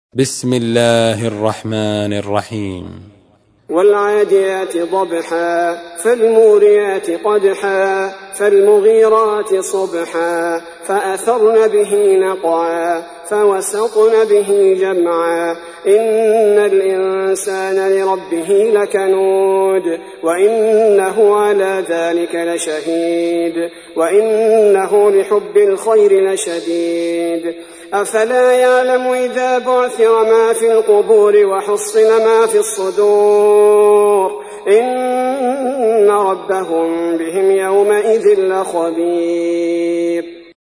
تحميل : 100. سورة العاديات / القارئ عبد البارئ الثبيتي / القرآن الكريم / موقع يا حسين